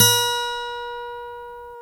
GTR 6-STR20Z.wav